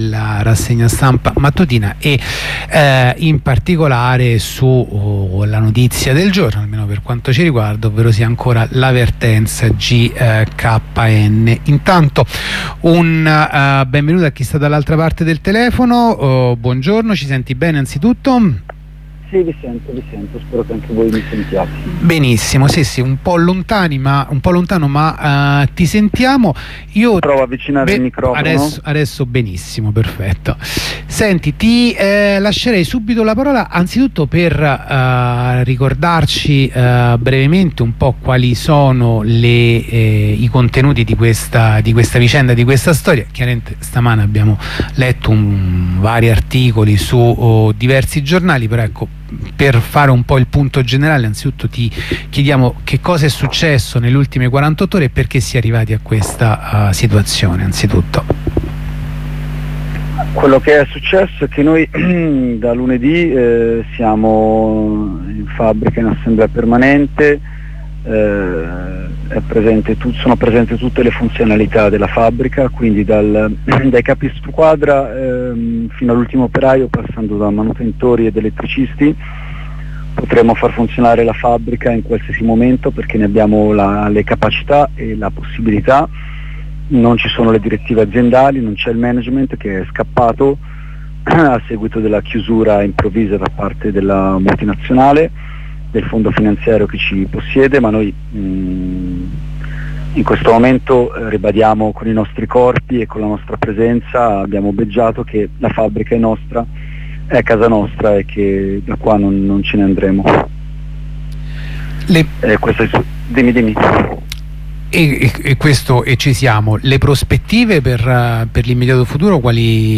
Corrispondenza con un compagno dei Cobas settore privato